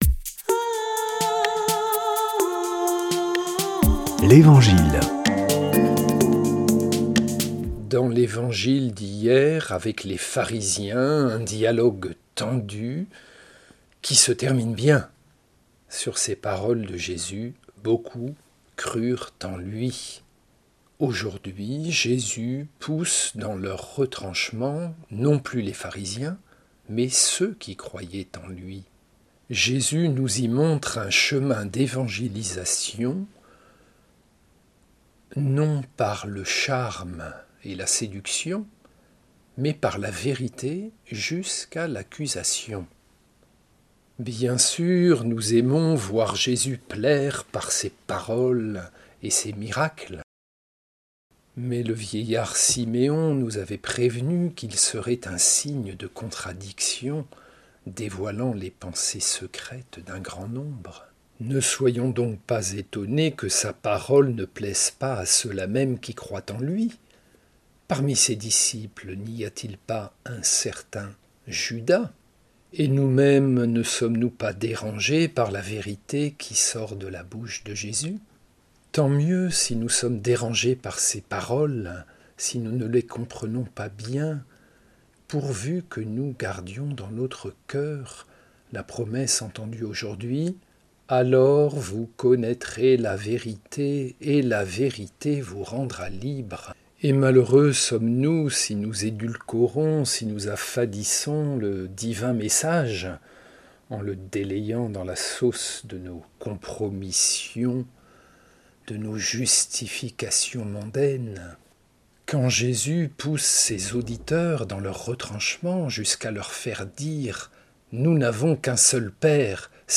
Commentaire
Des prêtres de la région